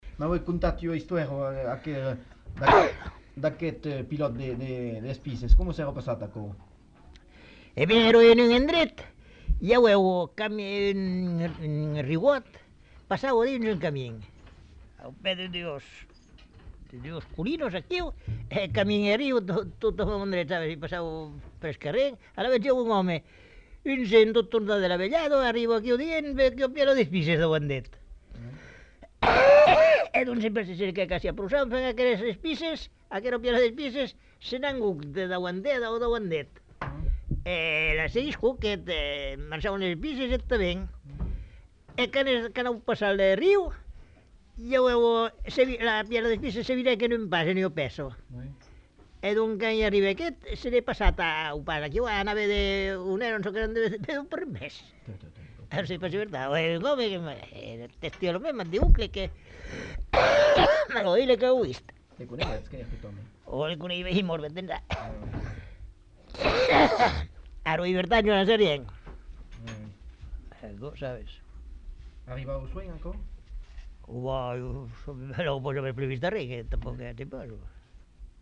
Lieu : Garravet
Genre : conte-légende-récit
Effectif : 1
Type de voix : voix d'homme
Production du son : parlé
Classification : récit légendaire